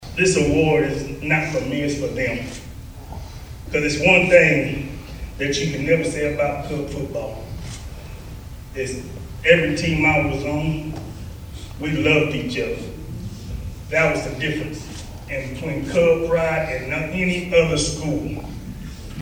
The Brenham Cub Football Hall of Honor welcomed four new members into their ranks before a packed house at the Blinn College Student Center this (Friday) afternoon.